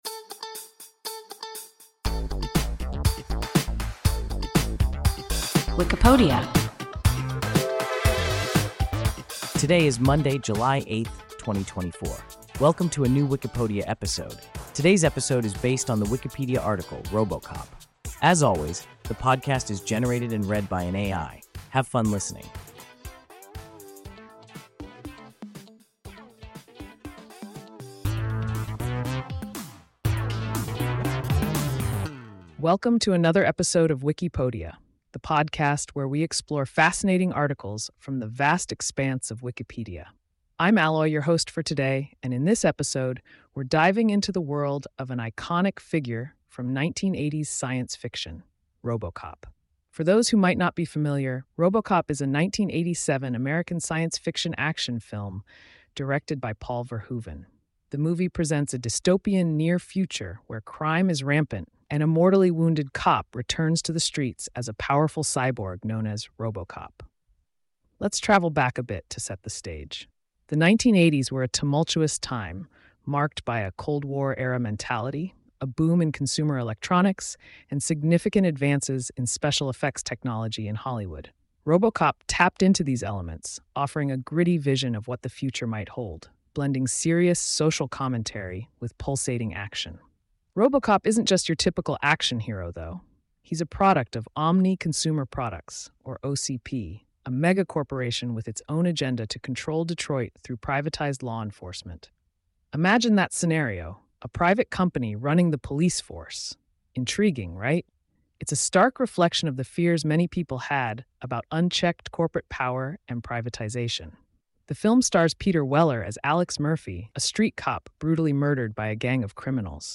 RoboCop – WIKIPODIA – ein KI Podcast